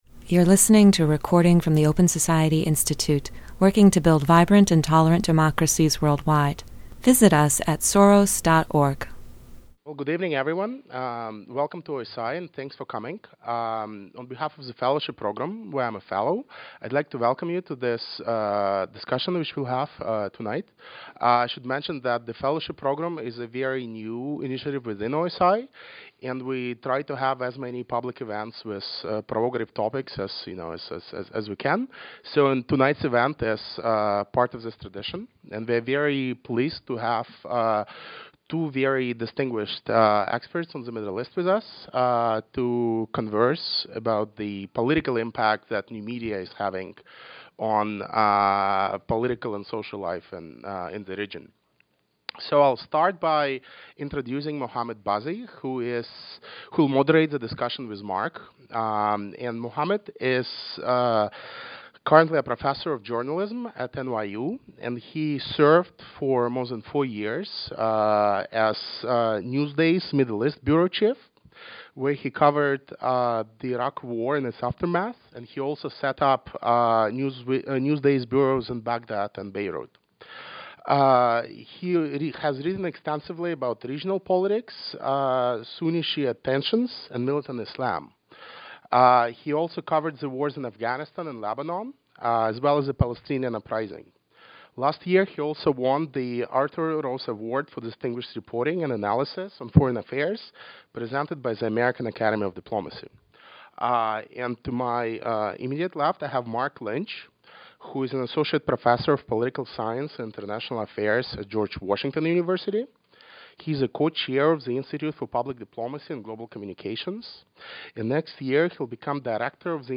Note: The audio for this event has been edited.